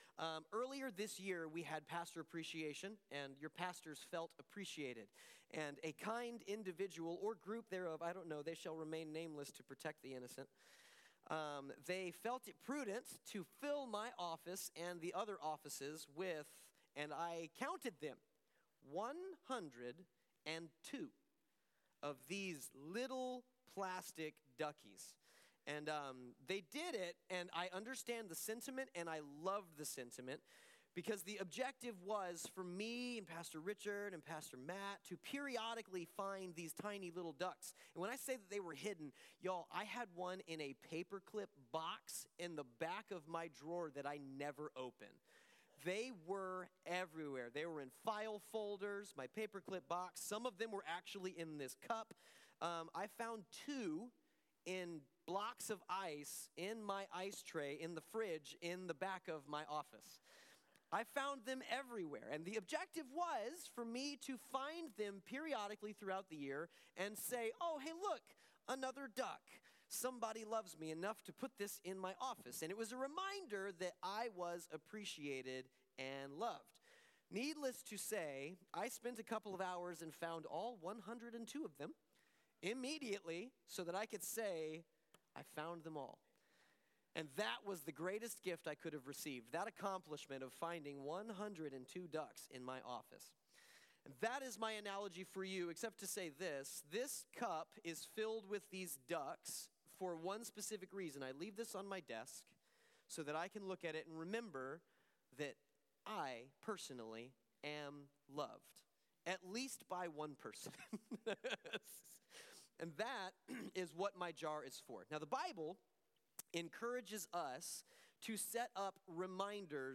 Sermon-11-9-25.mp3